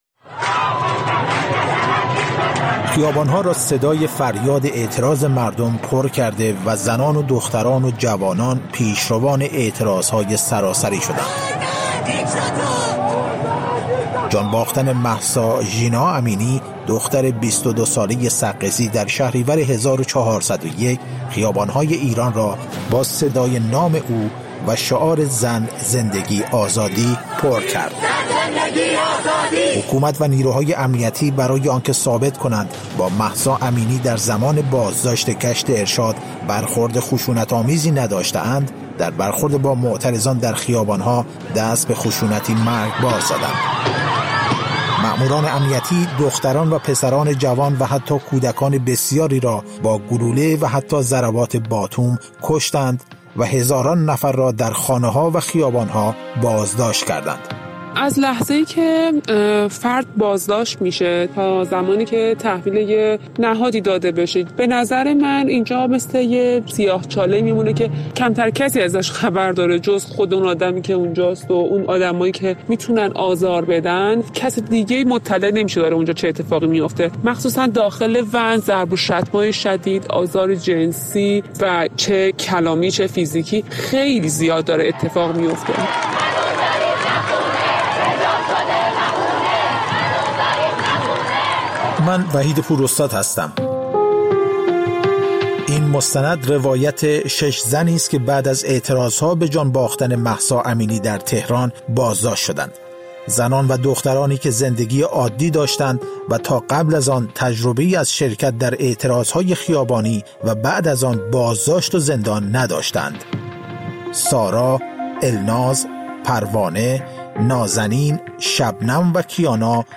مستند رادیویی: سیاه‌چاله؛ روایت زنان از و‌ن‌های پلیس